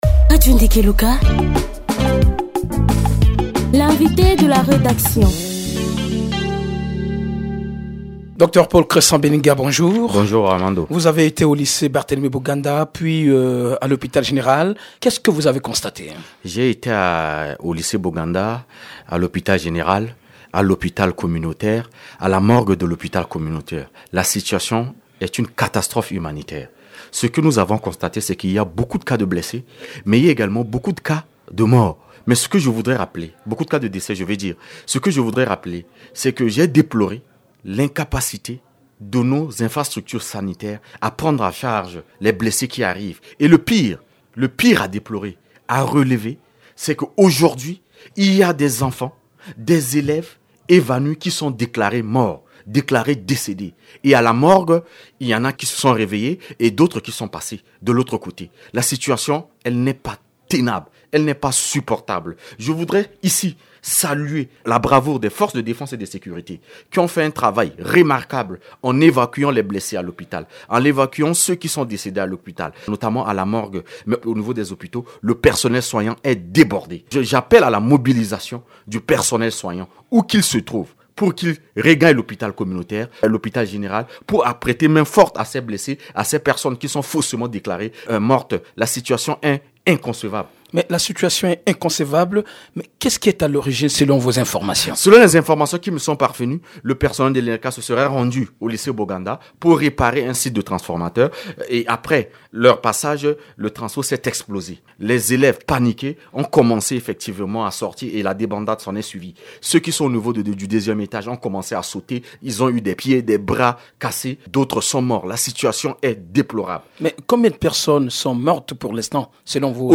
Il était l’invité de la rédaction jeudi matin.